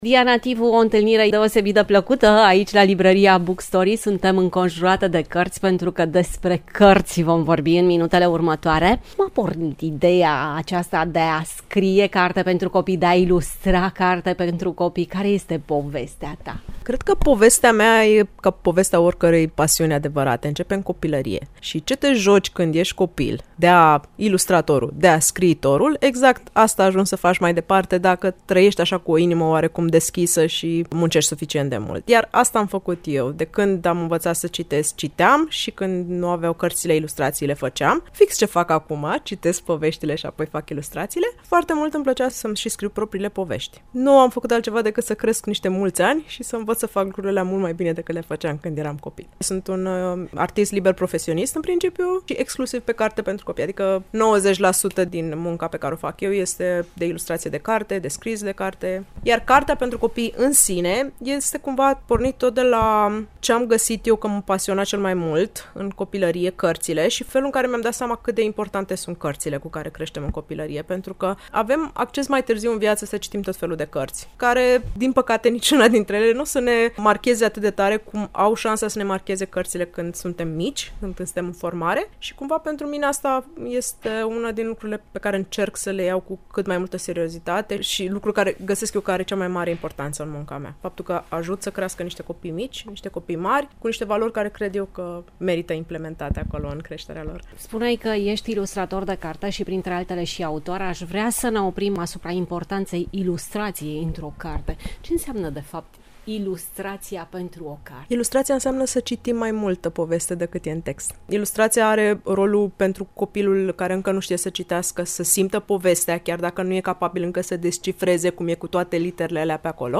Interviul audio realizat cu autoarea, deschide o fereastră către universul ei creativ și ne arătă câtă pasiune se află în spatele fiecărei pagini.